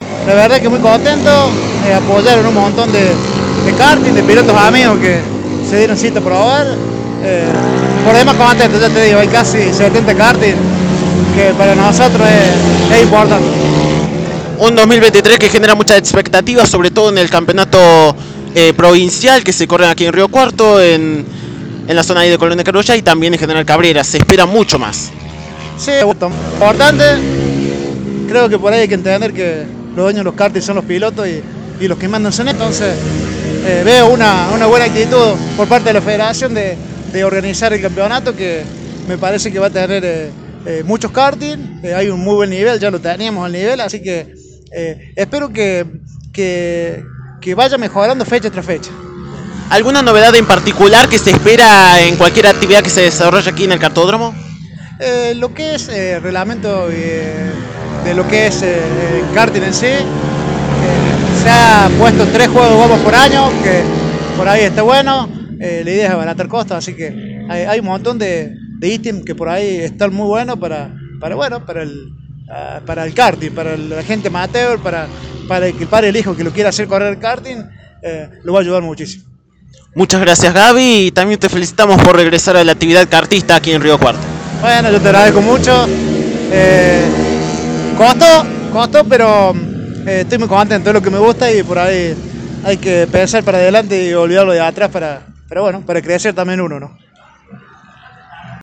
Durante las pruebas comunitarias del Karting de Asfalto Cordobés 2023 que se llevaron adelante el sábado en el trazado local